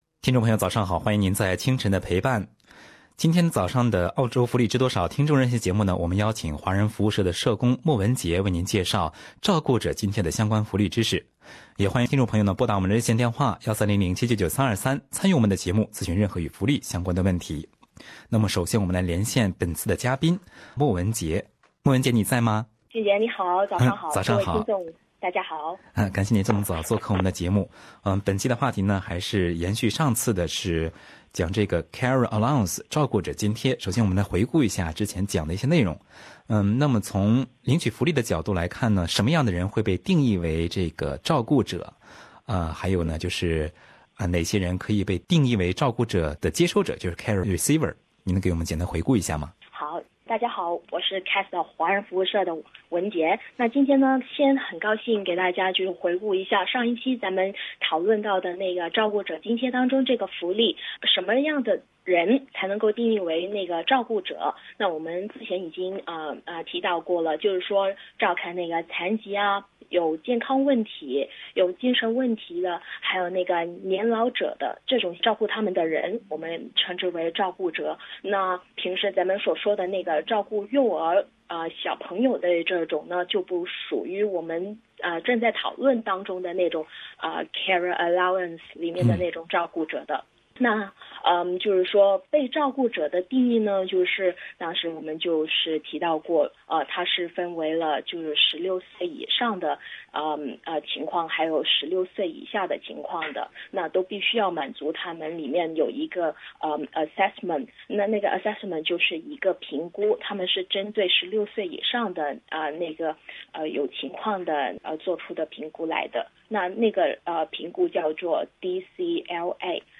本期的《澳洲福利知多少》听众热线节目